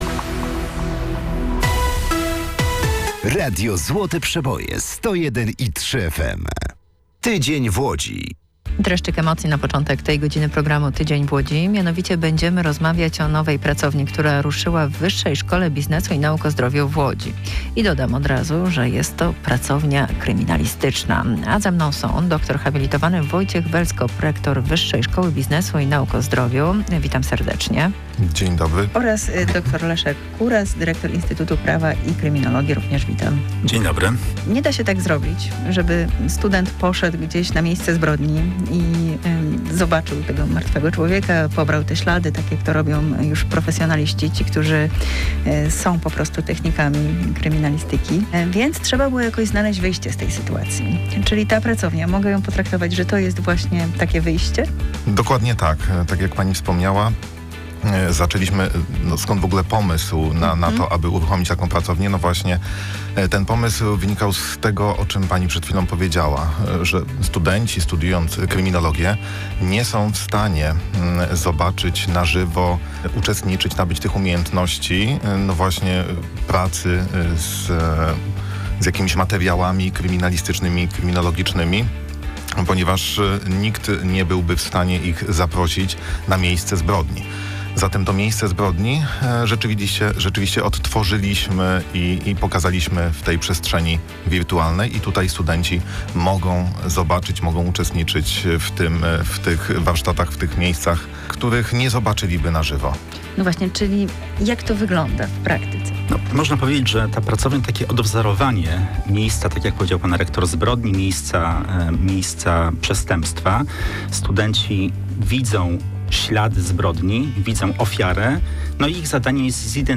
Wywiad w Radiu Złote Przeboje 1/2